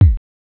bassdrum.wav